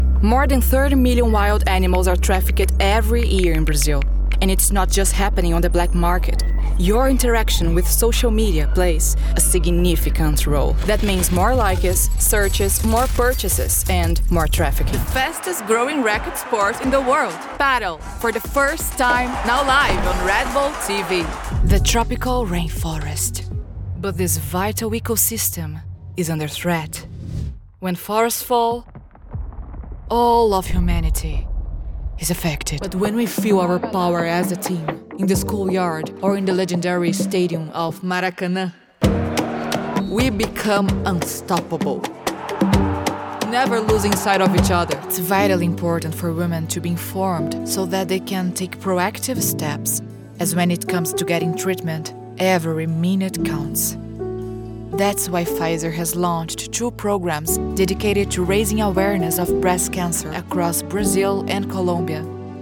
Muestras de voz en idiomas extranjeros
Demo comercial
Sin embargo, las habilidades vocales adquiridas en la última década ampliaron mi paleta a colores y matices ilimitados y siempre nuevos.
Los estudios me graban en sesiones dirigidas en vivo desde mi estudio en casa o reciben mi diligente grabación fuera de línea de múltiples tomas/variaciones.